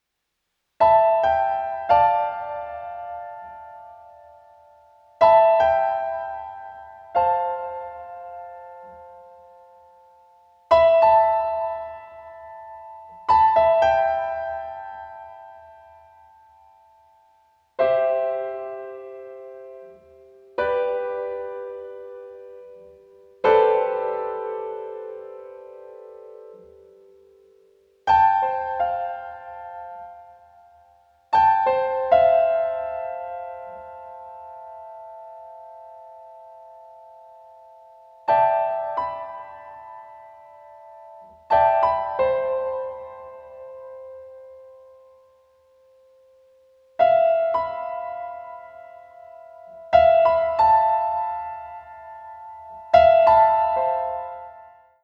solo album